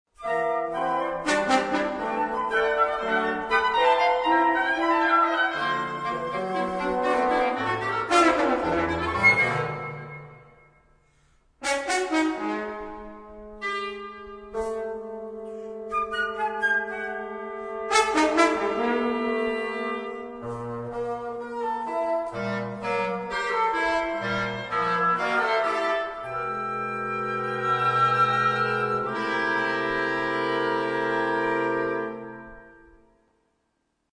flauta / flute
oboé / oboe
fagote / bassoon
trompa / French horn